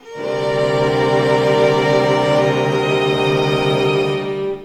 Index of /90_sSampleCDs/Zero-G - Total Drum Bass/Instruments - 2/track57 (Strings)